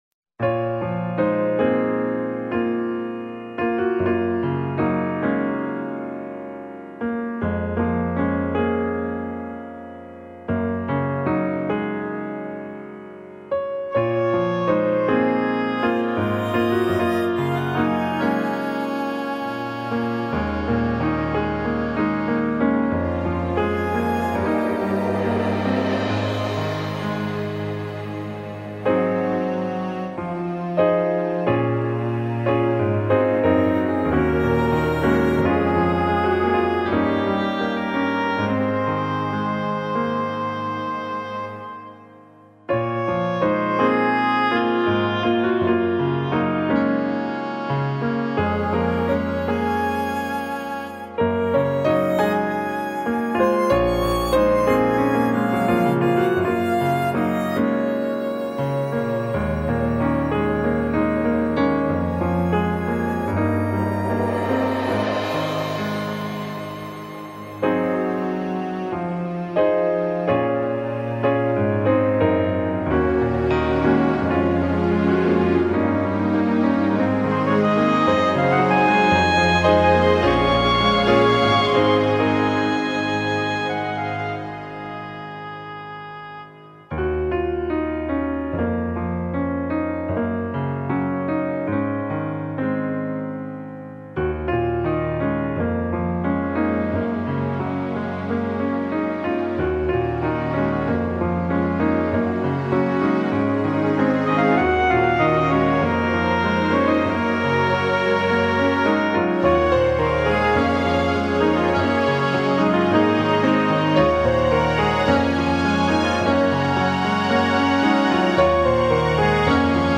A different recording of the same song, with synth orchestration added.
piano-song-with-electronic-strings.mp3